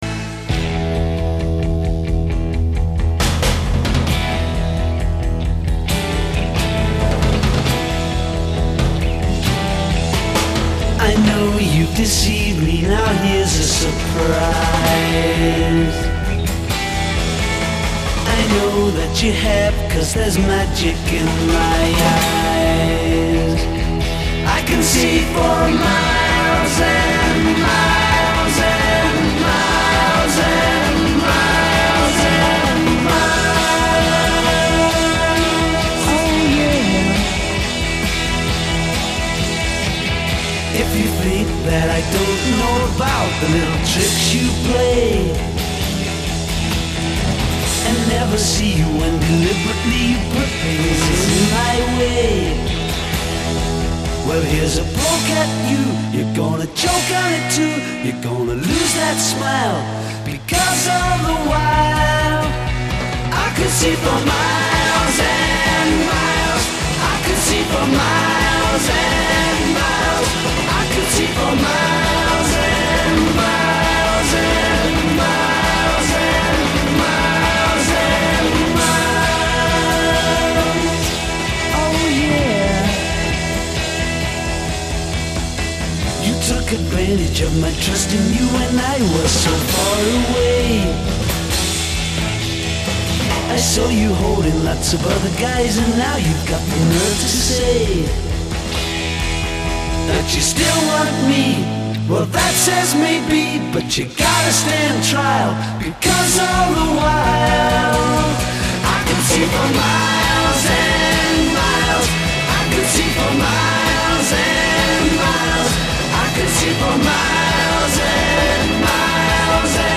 pre-chorus : 3-part vocal chromatic harmonies b
verse : Guitar solo (repeated notes) over chord pattern.
coda Extension of chorus (repeat and fade) e